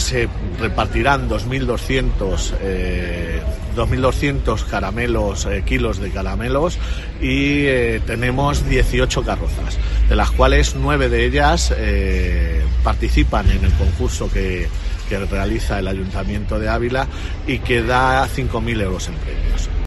El Concejal de fiestas es Carlos López y ha contado que el desfile contará con un tramo sin ruido para personas con trastorno del espectro autista y otro para personas con discapacidad fisica.